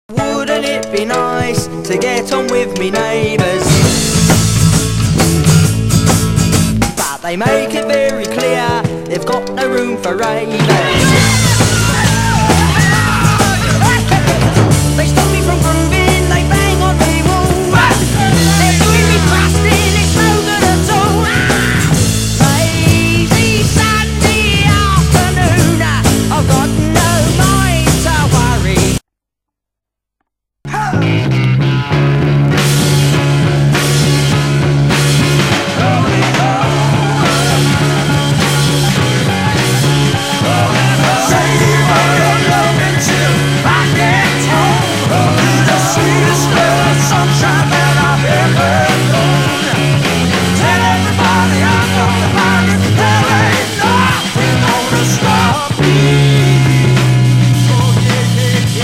A面は時代のコミカルでサイケなノリを反映させた、SEを取り入れたりと凝った作風のポップナンバー。